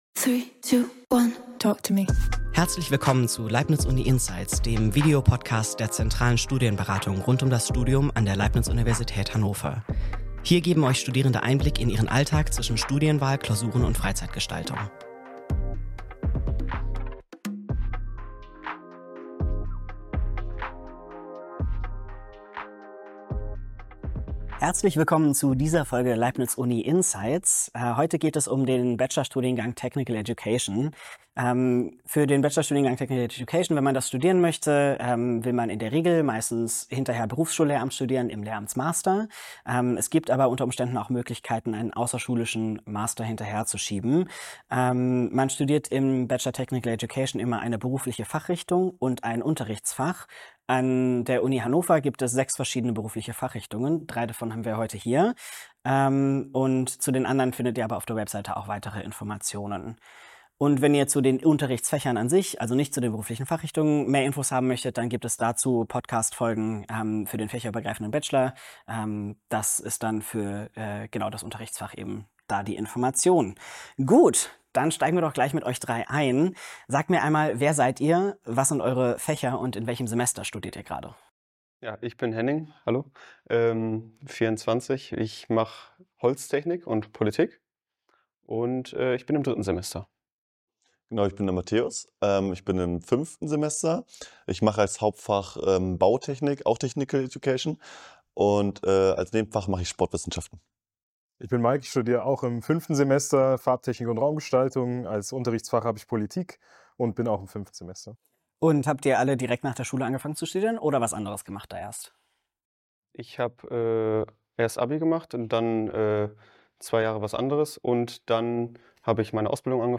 Warum wir Holztechnik, Bautechnik und Farbtechnik & Raumgestaltung im Bachelorstudiengang Technical Education studieren ~ Leibniz UNInsights - Der Studitalk der Leibniz Uni Hannover Podcast